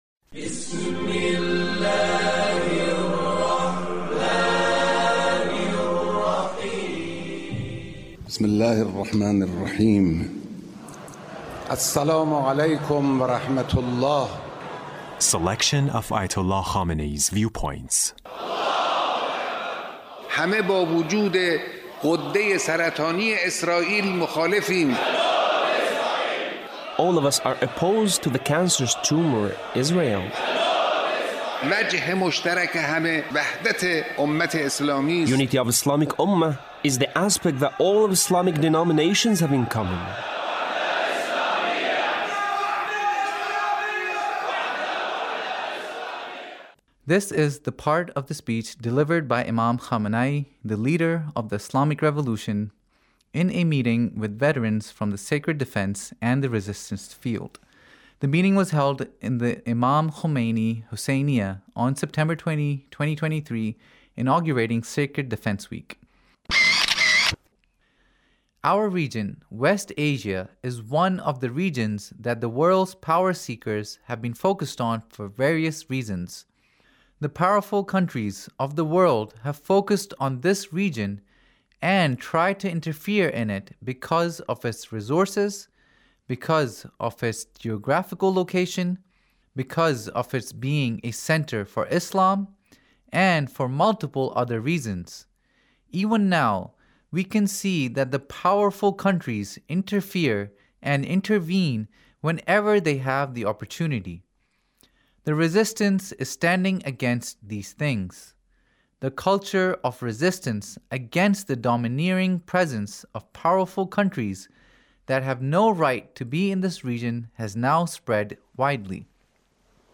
Leader's Speech (1883)